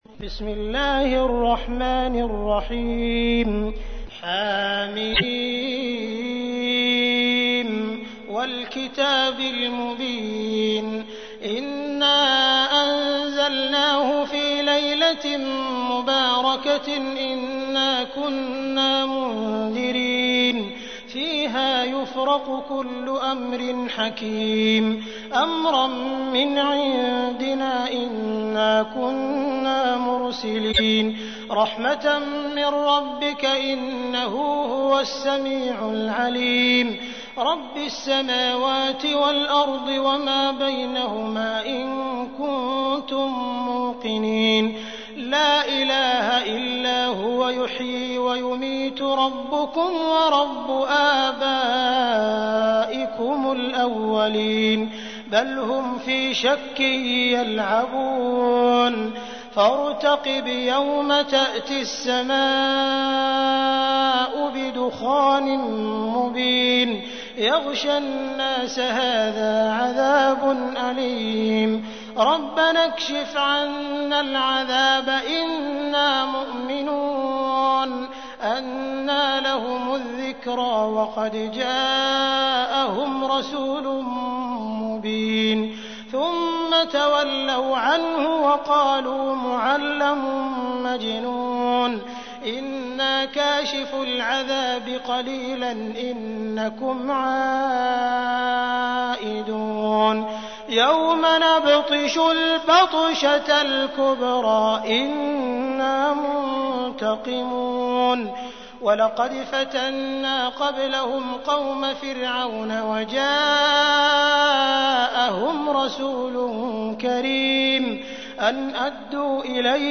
تحميل : 44. سورة الدخان / القارئ عبد الرحمن السديس / القرآن الكريم / موقع يا حسين